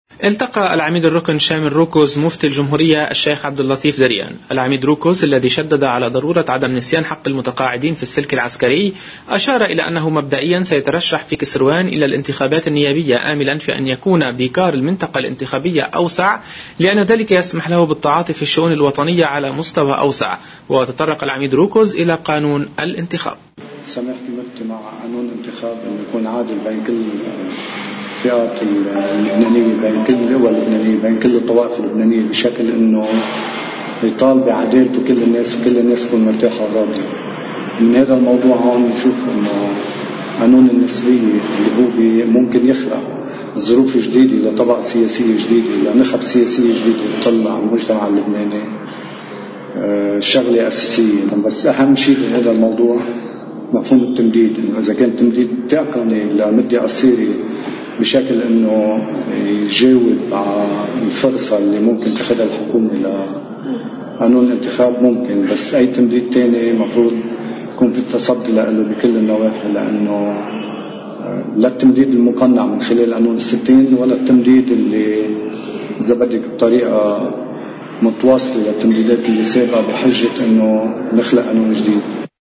مقتطف من حديث العميد شامل روكز اثر لقائه المفتي دريان: